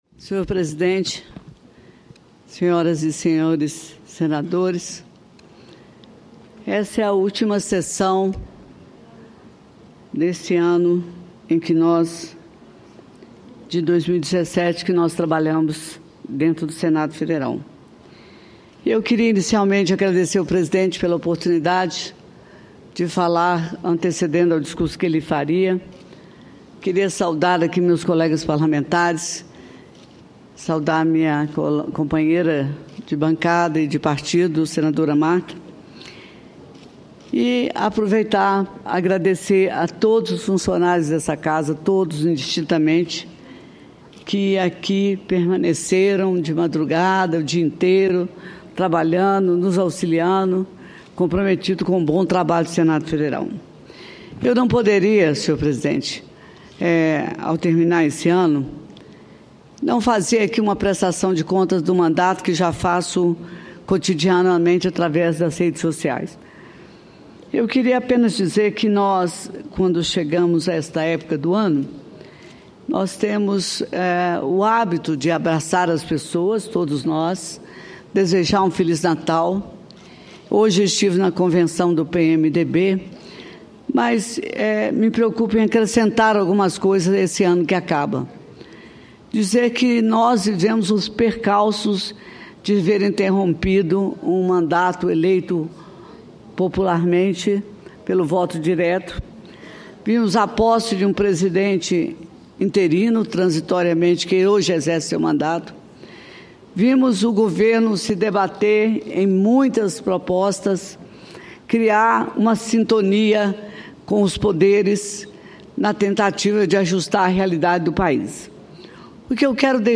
Discursos